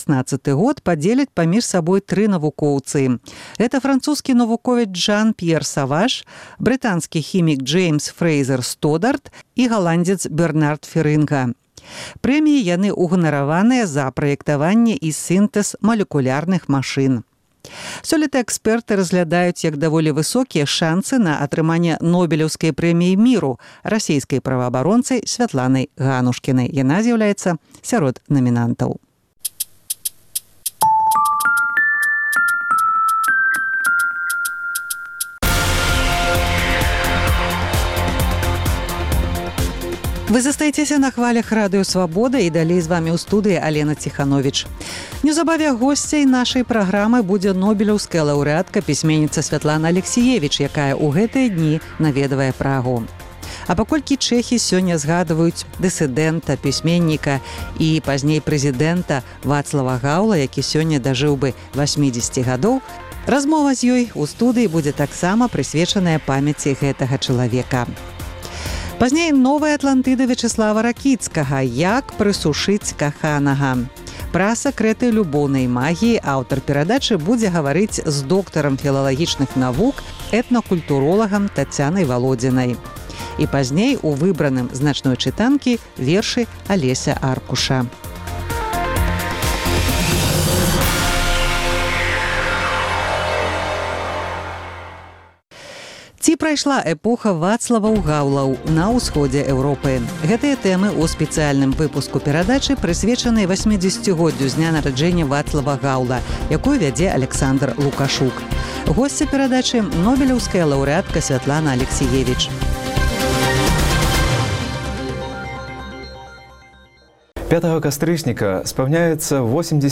Госьця перадачы – Нобэлеўская ляўрэатка Сьвятлана Алексіевіч.